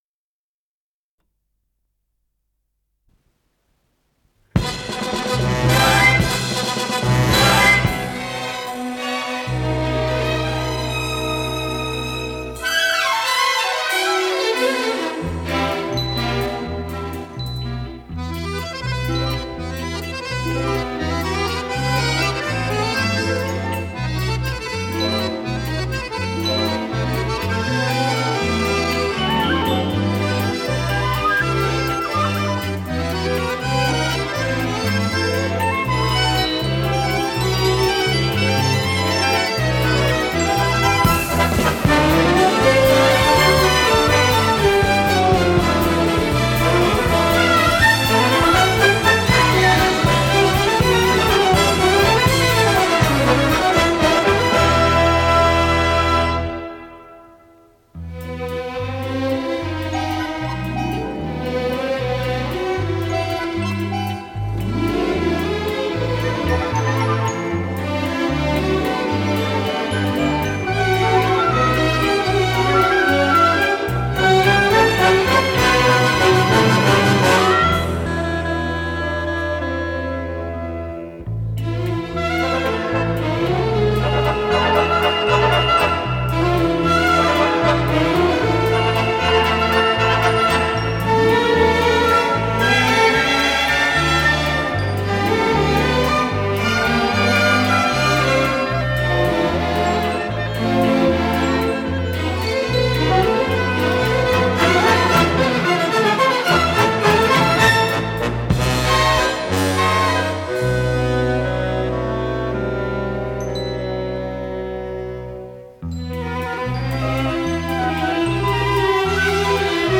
вальс, ми минор
ВариантДубль моно
Тип лентыORWO Typ 104